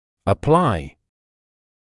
[ə’plaɪ][э’плай]применять; прикладывать; прилагать, приложить (силу); употреблять; использовать